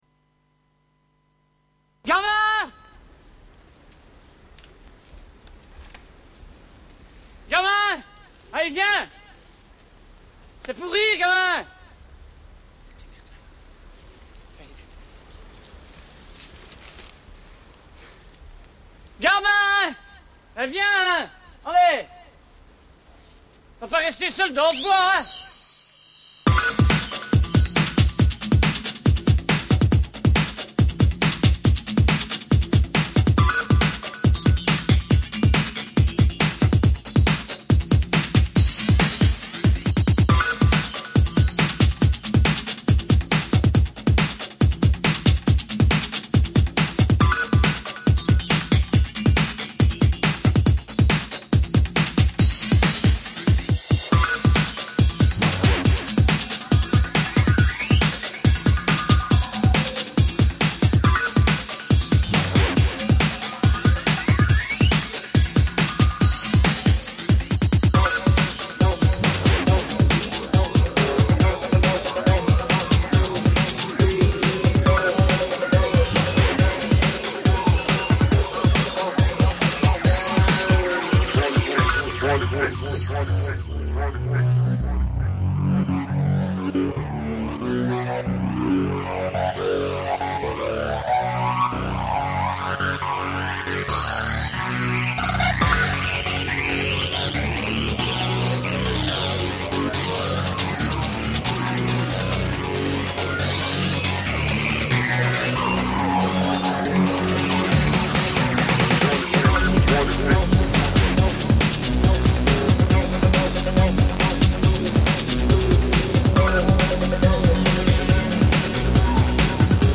NuSkoolBreaks